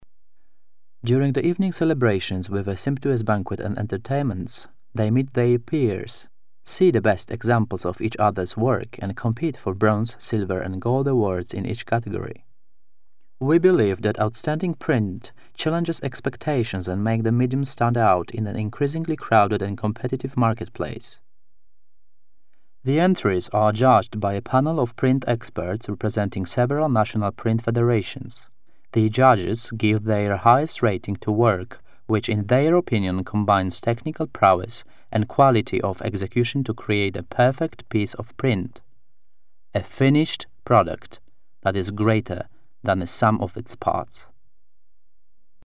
POLISH. Experienced young actor and VO.